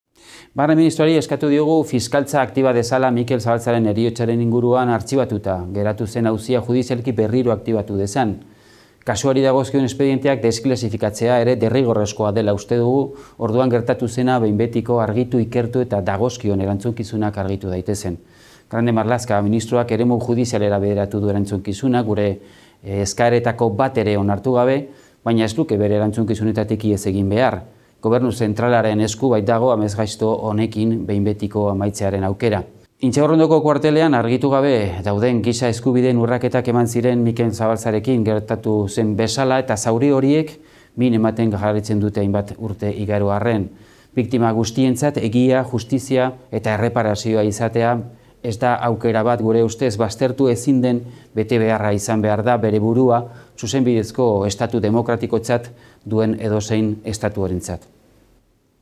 Gobernuaren kontrol saioan egindako galderan, kasuari dagozkion espedienteak desklasifikatzeko ere eskatu du, behin betiko argitu, ikertu eta dagozkion erantzukizunak har daitezen.